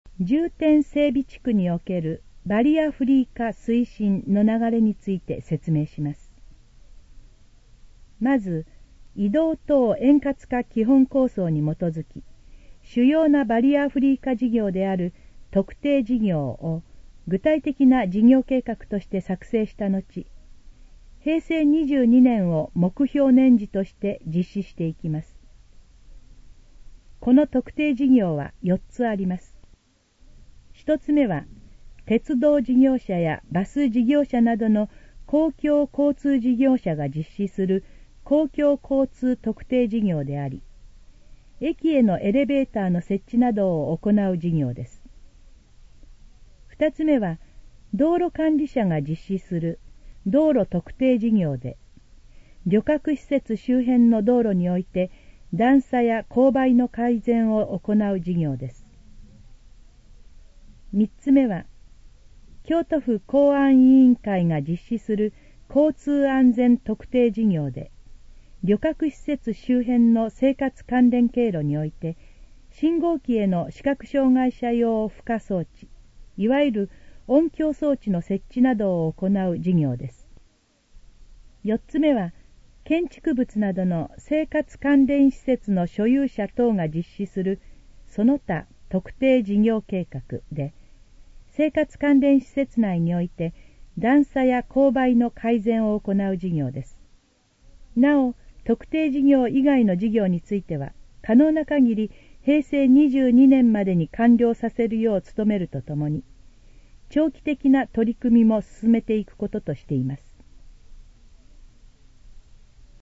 このページの要約を音声で読み上げます。
ナレーション再生 約442KB